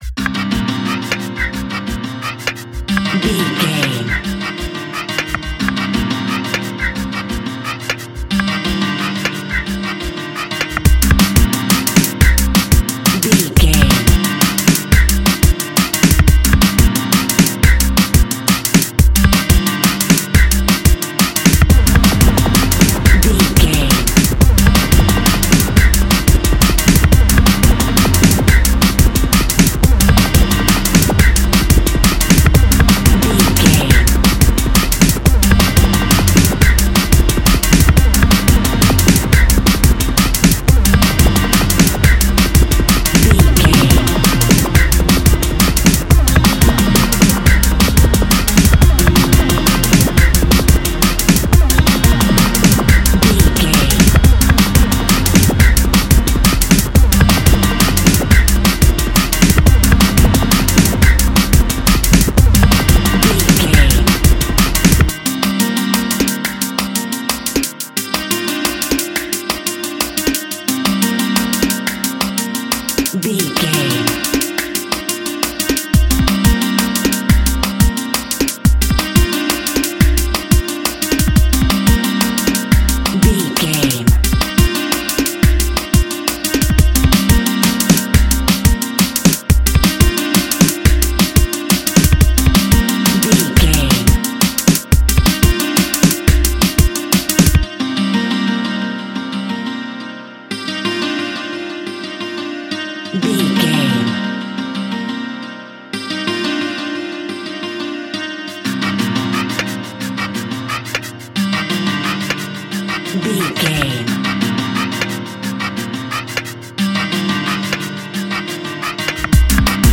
Aeolian/Minor
Fast
futuristic
hypnotic
industrial
frantic
aggressive
dark
powerful
drums
synthesiser
sub bass
synth leads
synth bass